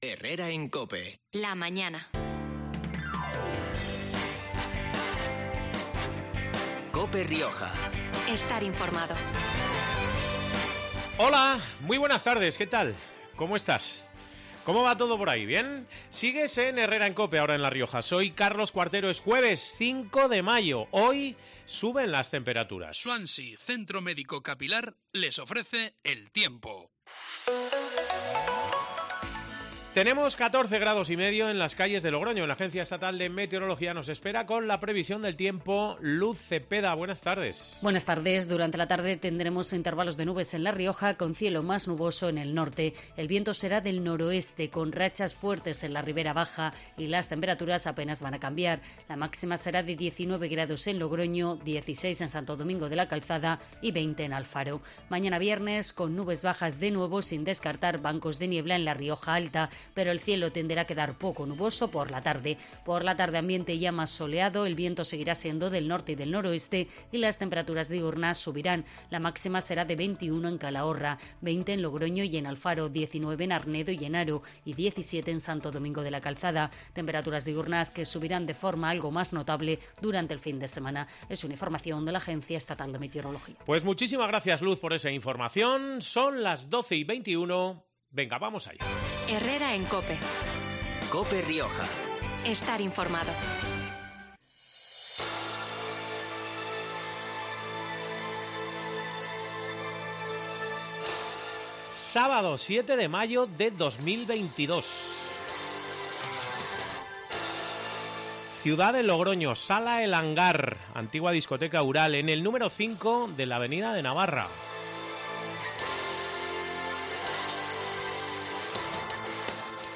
Hablamos del primer festival 'Metal Woman' (Logroño, 7 de mayo, sala 'El Hangar') con tres miembros de la banda riojana 'Ethernal', liderada por la...